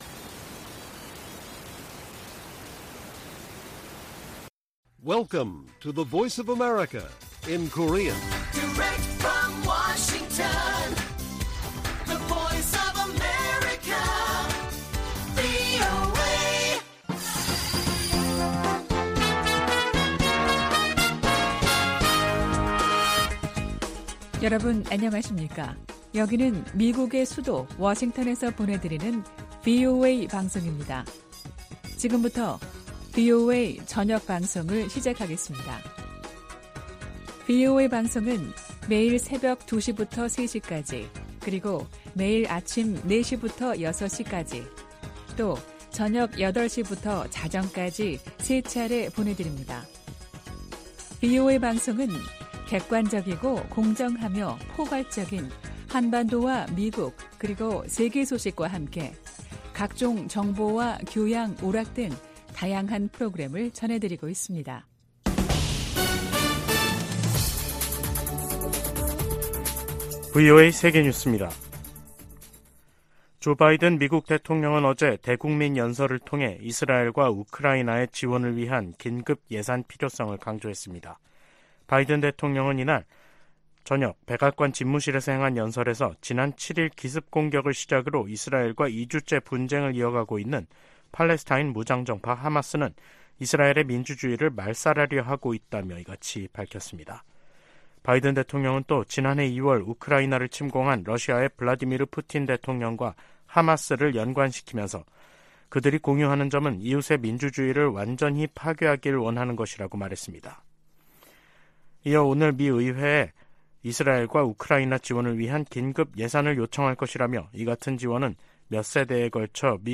VOA 한국어 간판 뉴스 프로그램 '뉴스 투데이', 2023년 10월 20일 1부 방송입니다. 김정은 북한 국무위원장이 19일 세르게이 라브로프 러시아 외무장관을 접견하고 안정적이며 미래지향적 양국 관계의 백년대계를 구축하자고 말했습니다. 미 국무부가 북-러 무기 거래를 좌시하지 않을 것이라는 입장을 밝혔습니다. 로이드 오스틴 미 국방장관이 19일 신원식 한국 국방장관과 통화에서 북한 위협 대응과 미한일 3국 협력의 중요성 등에 관해 논의했습니다.